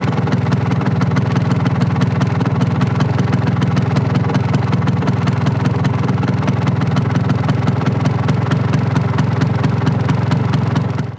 Moto marca Vespa en punto muerto
motocicleta
Sonidos: Transportes